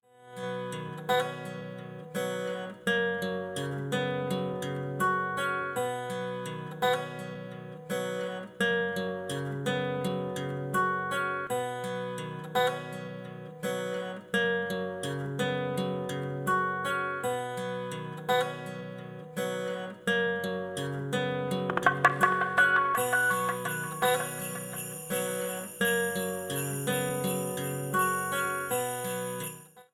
This is an instrumental backing track cover.
• Key – B
• Without Backing Vocals
• With Fade